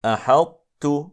8. Ţā' [الطاء — ط] in i Tā' [التاء — ت]:
Det är ofullständig idgham (إدغامٌ ناقص); eftersom en stark bokstav inte går in helt i en svag bokstav, så brukade araberna slå samman det icke-vokaliska (Ţā' — ط) i (Tā' — ت), samtidigt som det bevarade egenskapen av vidhäftning (iţbāq — الإطباق) från det, vilket händer när reciteraren stänger hans/hennes tunga på en (Ţā' — ط) utan qaqalah och sedan tar han/hon bort den från en vokal (Tā' — ت), som i: